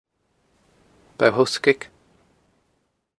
W. J. Watson's notes: Map name appears in: Local tradition Feature Co-ordinates: 57.3588,-5.831 Show on map: Click to view on google map Audio: Click to hear placename audio Image: